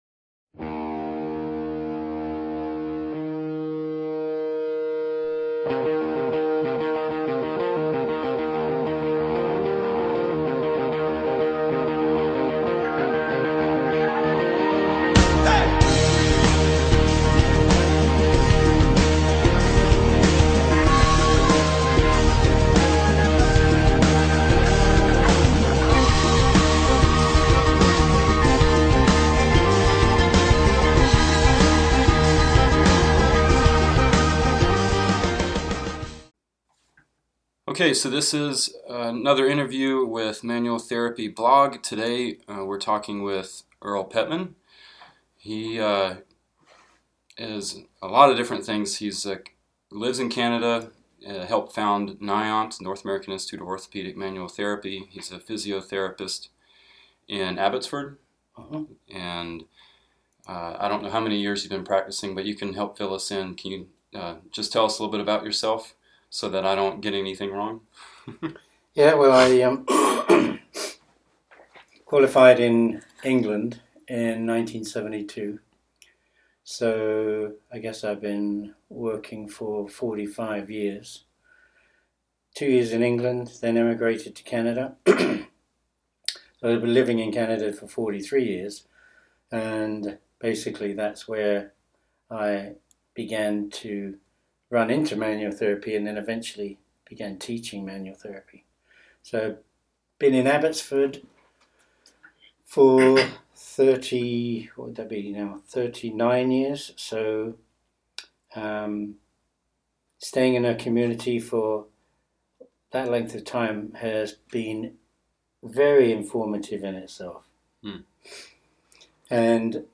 Welcome to our second interview!